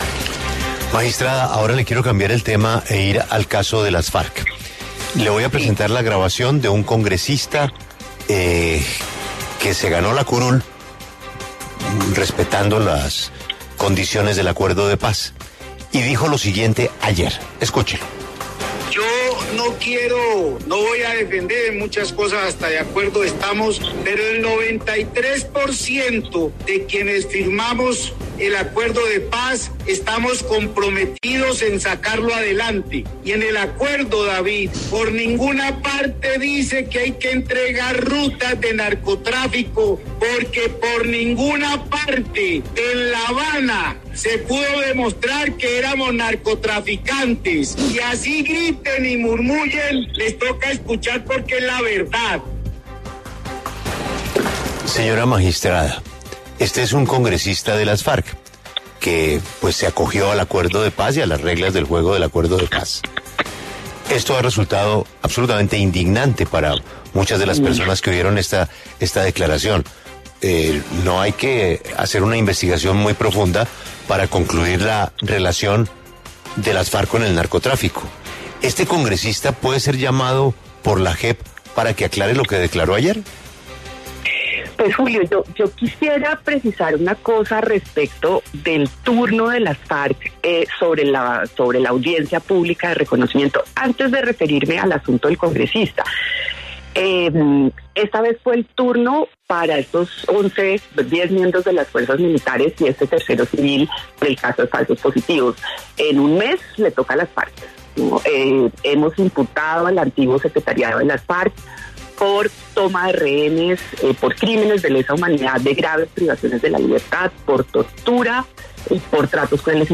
La magistrada Catalina Díaz habló en La W sobre las afirmaciones del congresista del partido Comunes, Marcos Calarcá, quien aseguró que nunca pudo probarse que las extintas Farc eran narcotraficantes.